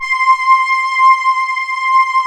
Index of /90_sSampleCDs/USB Soundscan vol.28 - Choir Acoustic & Synth [AKAI] 1CD/Partition D/14-AH VOXST